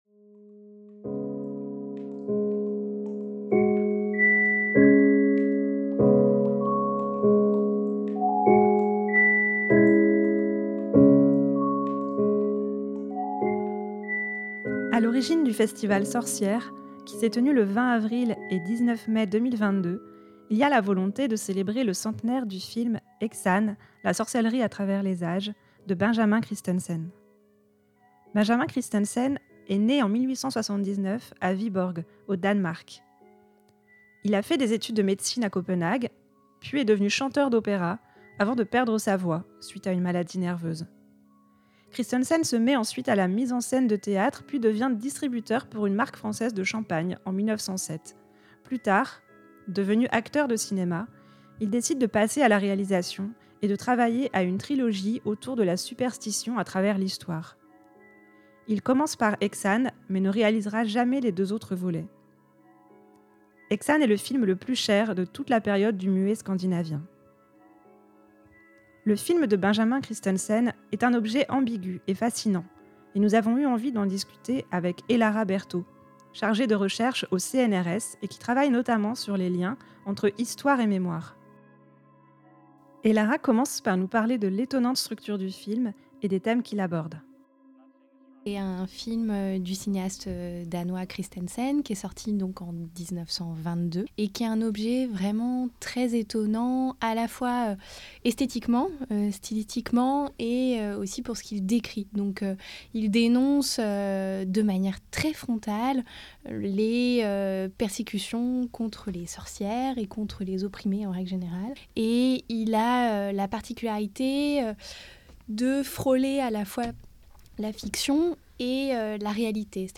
Hors-série - Festival Sorcière(s) / Häxan, entretien